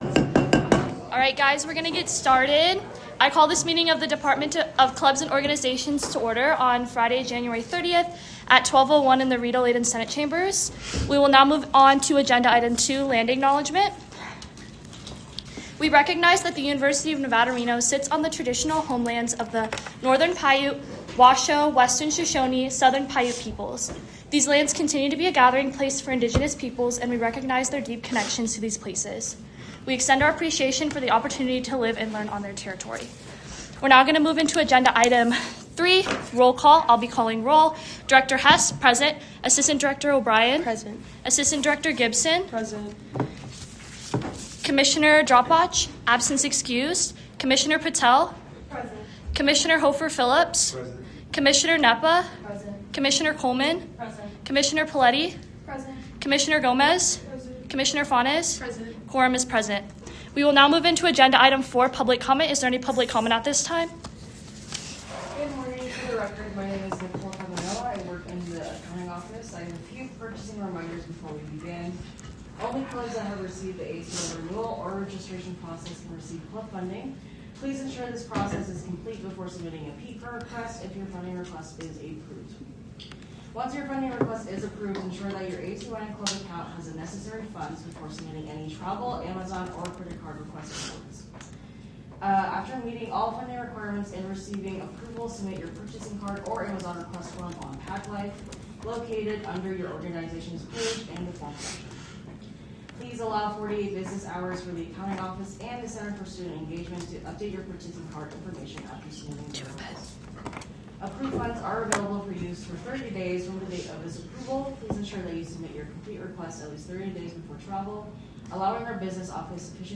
Location : Rita Laden Senate Chambers - located on the third floor of the JCSU
Audio Minutes